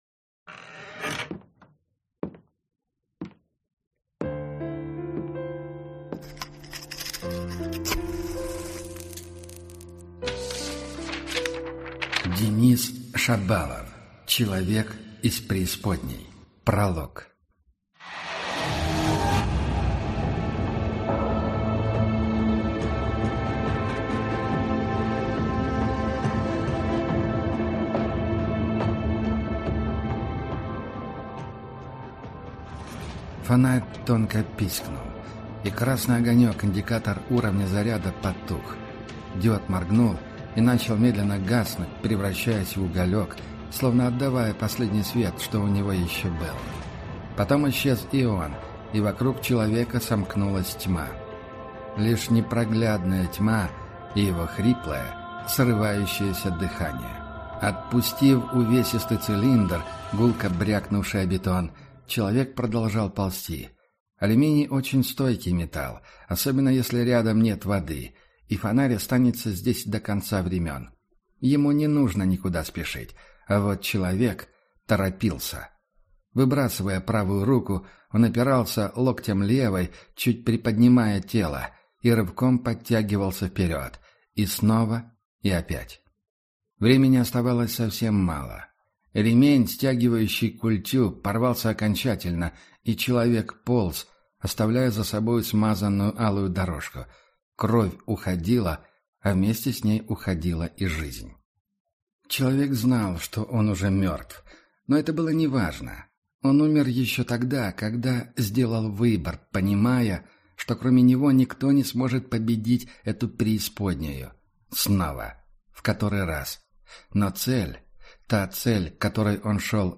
Аудиокнига Человек из преисподней. Дом | Библиотека аудиокниг